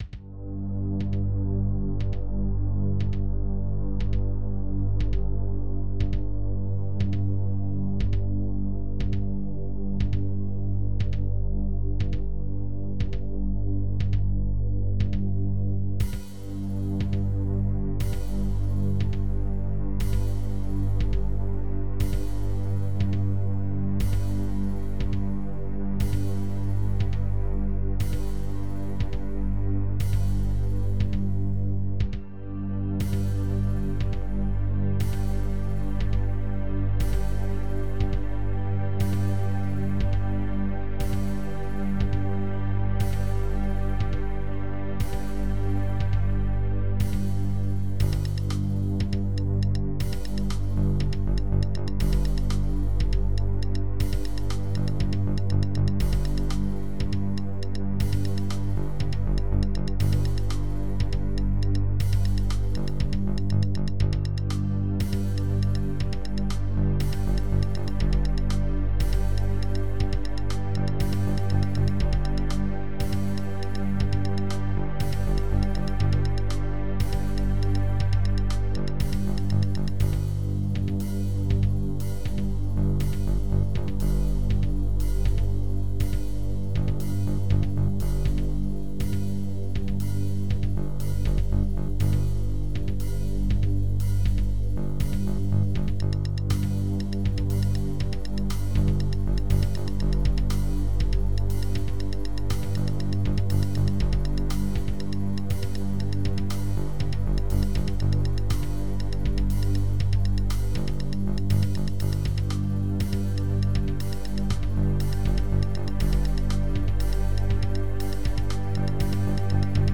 This track uses a couple tricks that make it more tense.